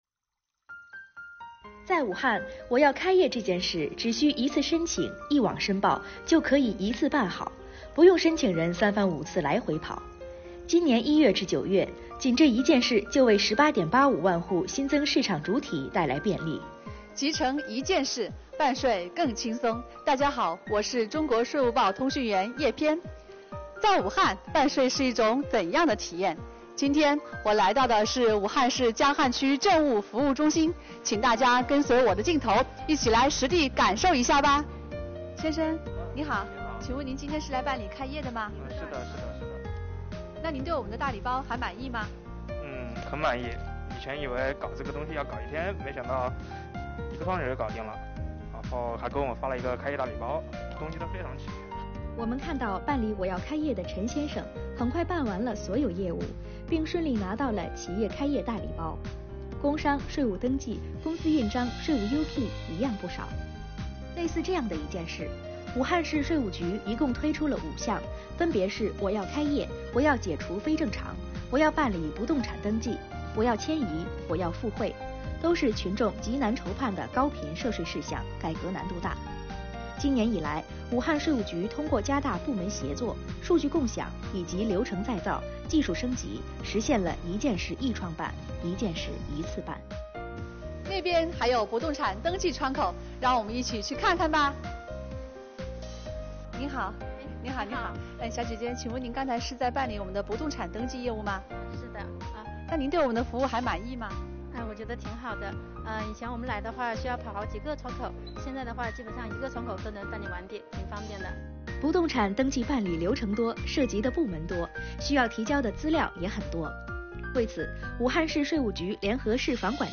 旁白